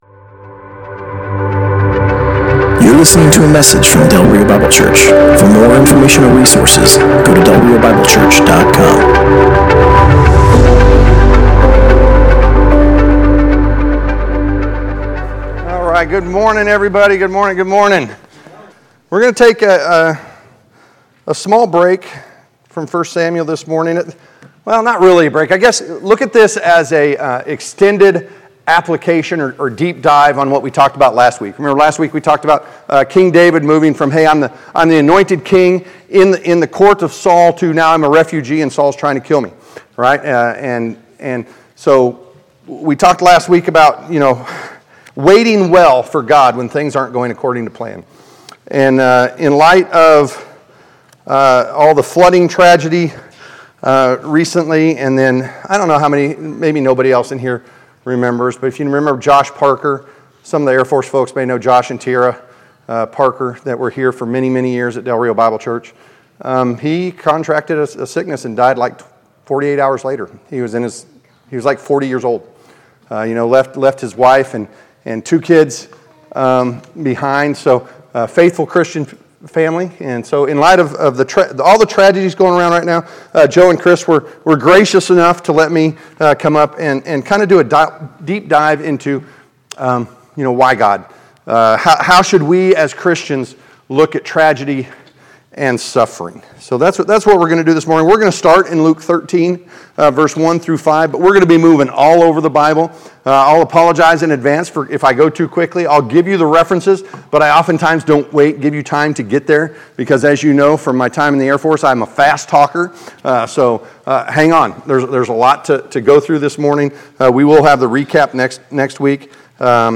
Passage: Habakkuk 3:17-19 Service Type: Sunday Morning